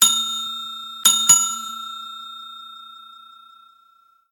notification.ogg